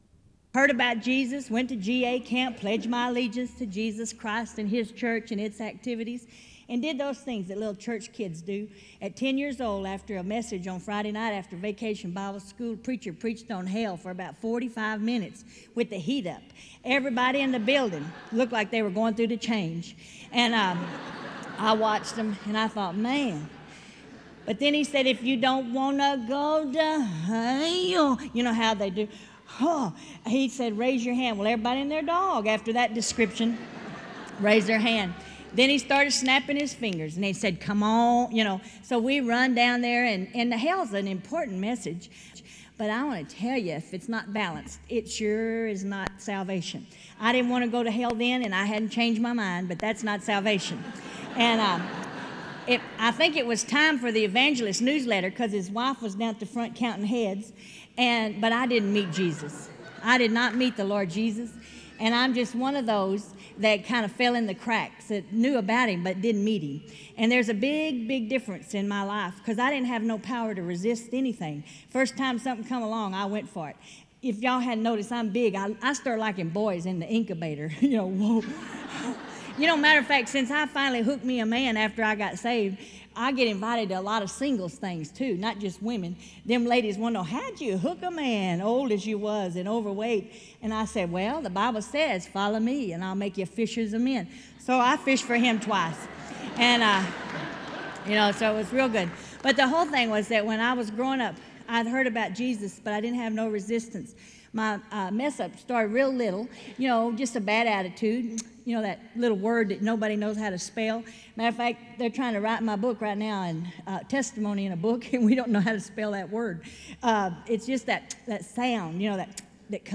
In Collection: SEBTS Chapel and Special Event Recordings - 2000s